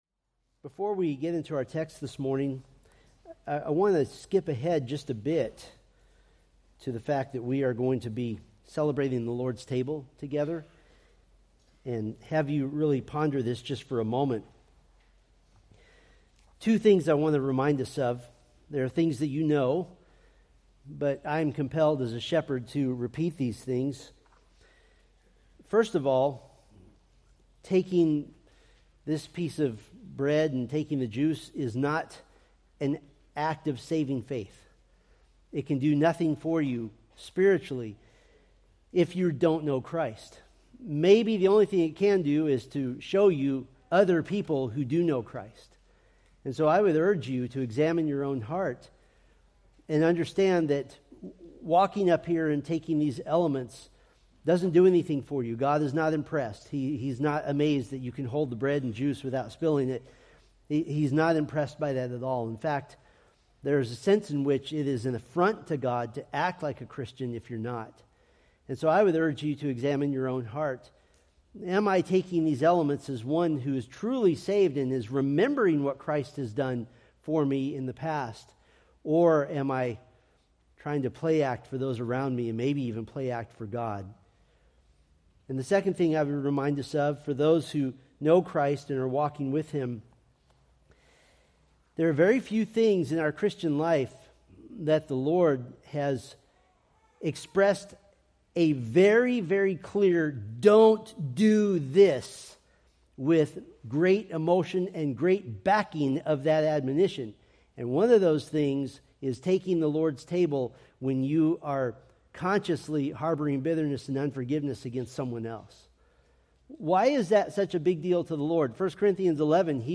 Preached November 10, 2024 from Matthew 9:9-17